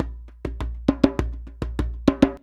100DJEMB19.wav